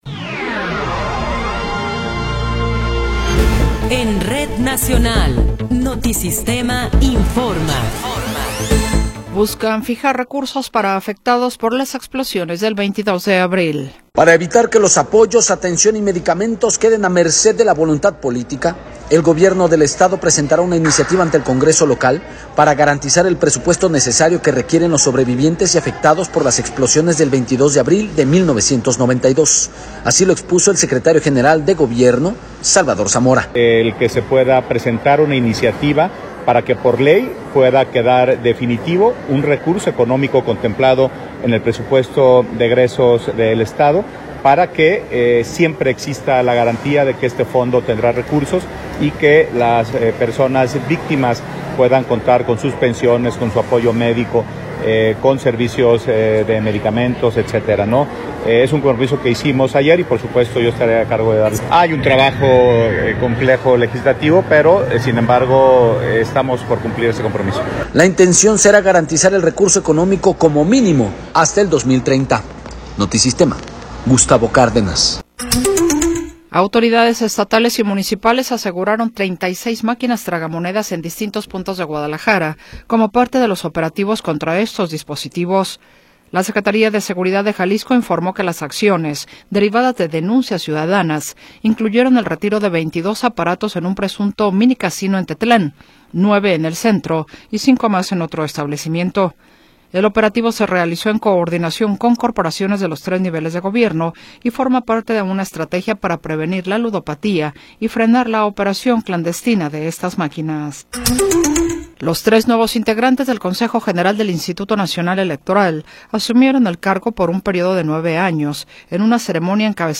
Noticiero 17 hrs. – 22 de Abril de 2026